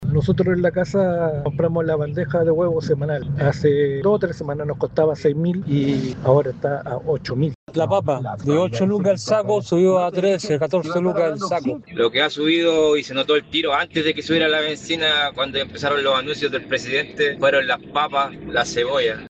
Sobre este escenario, auditores detallaron a Radio Bío Bío cómo han cambiado los precios en las últimas semanas.